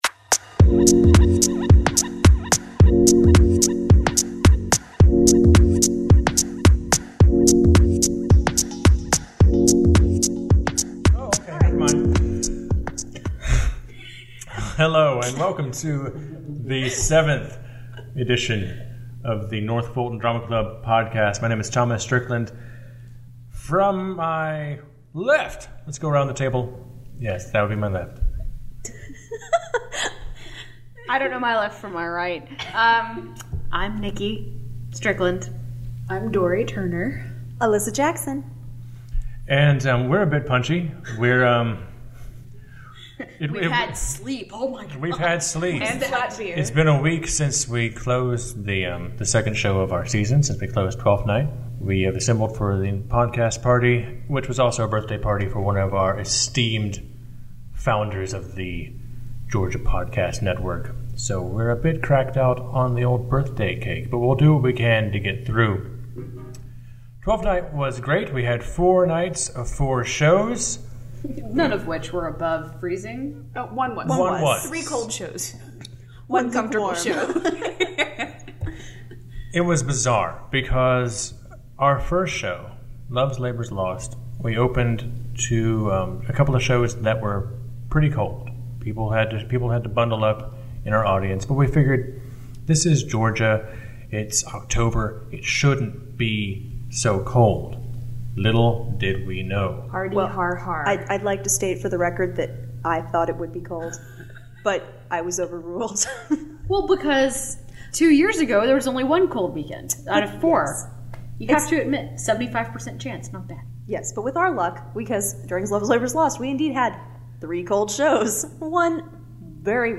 Show notes In this episode, we look back on some favorite on-stage and backstage moments from our recent production of Twelfth Night . In just under twenty minutes, we ramble on about everything from disassembling turbans to ringing candelabras. Our banter is witty, quick and maybe a little confusing, but we had a great time recording it all.